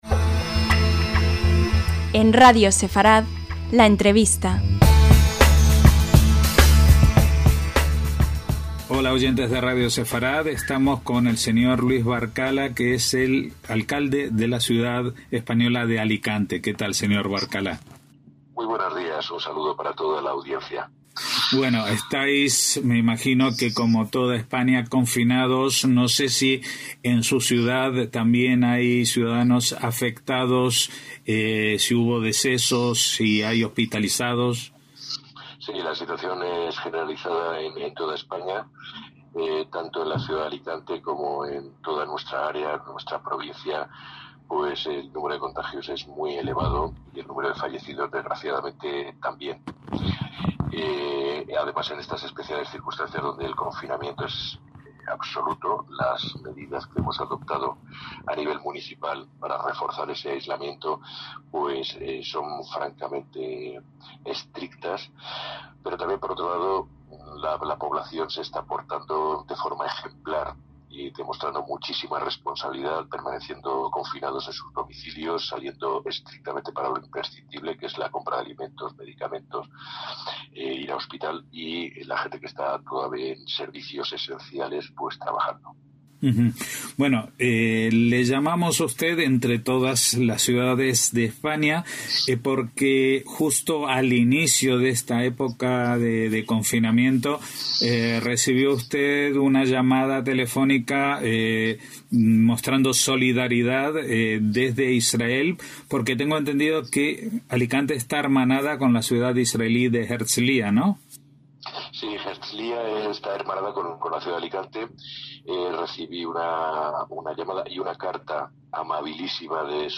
LA ENTREVISTA - En estos días en que prácticamente todo el planeta comparte sufrimiento, miedo y esperanza, pequeños gestos pueden llegar a ser muy reconfortantes, como cuando el alcalde de Alicante, Luis Barcala, recibió en los primeros días del Estado de Alarma decretado en España una carta de su homólogo de la ciudad israelí hermanada con la española, Herzliya (en el centro del país), solidarizándose con una situación de confinamiento y decesos que, a día de hoy, también se vive en Israel. Esta corporación municipal ha destacado por su respeto e interés por los temas vinculados a sus raíces sefardíes, el contacto con su comunidad judía o el recuerdo y homenaje a las víctimas del Holocausto.